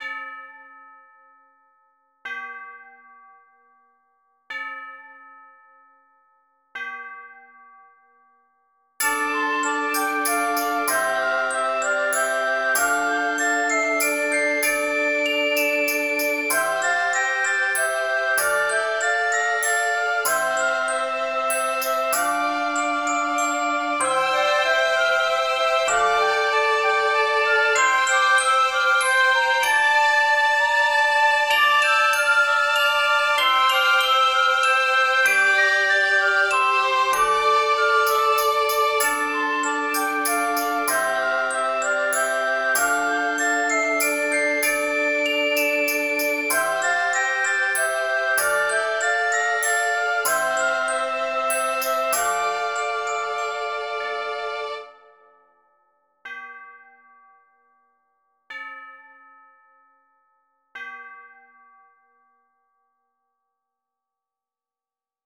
Mysterious World - Glittering Crystals
Category 🌿 Nature
ambient ancient bell bells calm carillon chime church-bell sound effect free sound royalty free Nature